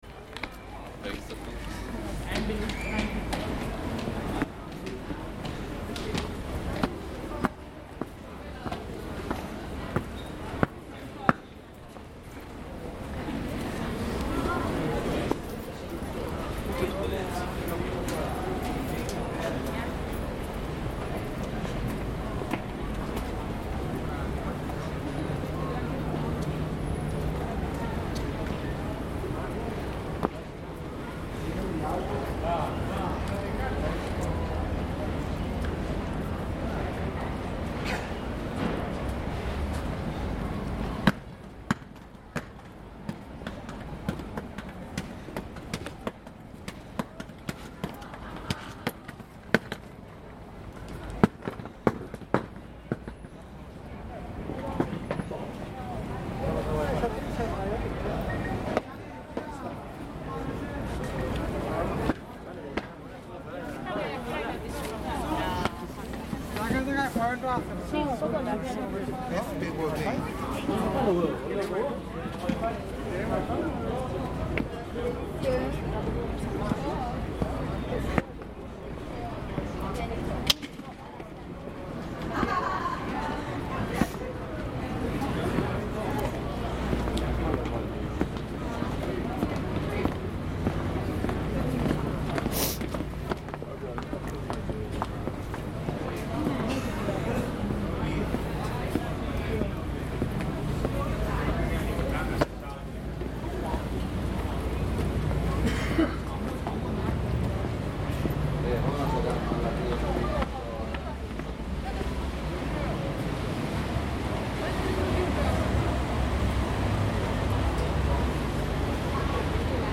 The sounds of footsteps on the Rialto bridge, footsteps, snippets of conversations from passersby and vendors, the lapping of water at the footsteps to the bridge, the cry of gulls, and the sounds of passing boats.
This is a field recording I took of walking over the Rialto bridge, an heritage space of culture, bartering, and tourism for centuries.
UNESCO listing: Venice and its Lagoon